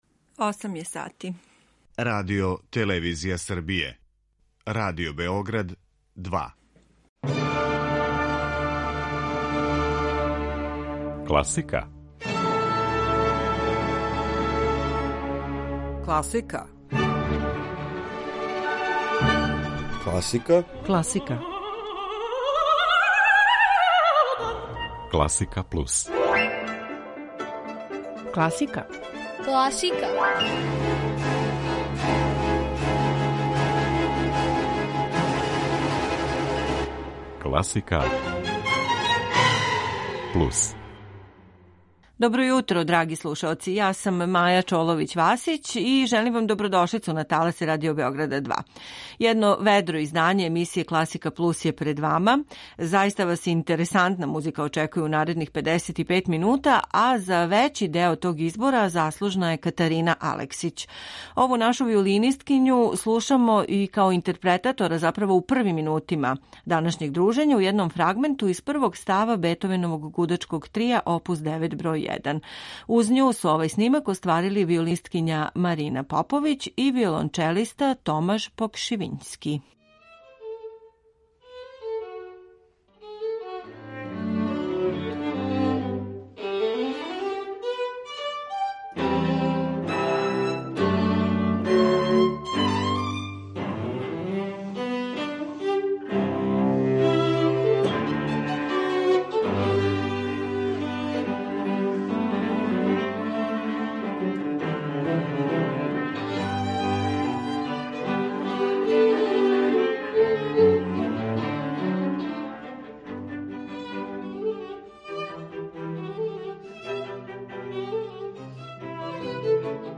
Јутро уз класику
У рубрици „На други начин" слушамо Шпански капричо Николаја Римског-Корсакова у необичном аранжману за четири гитаре.